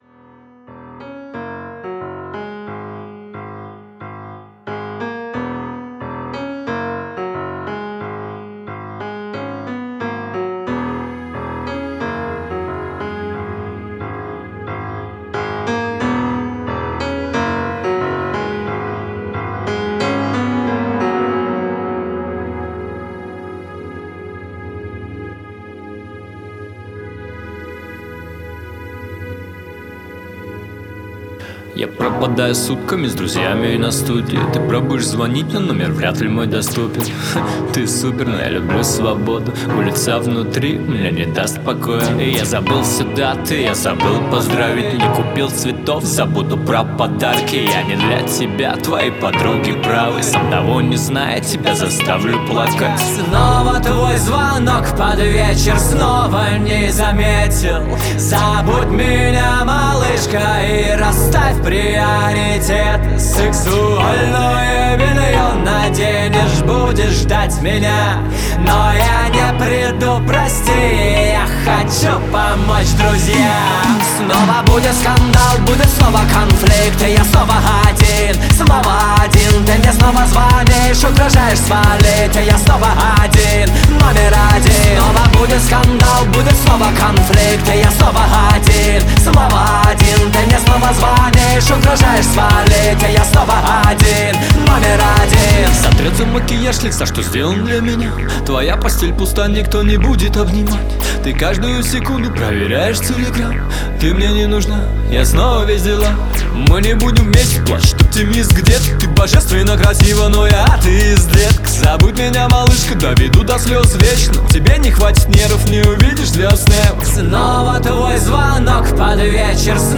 в жанре хип-хоп
его голос передает искренние эмоции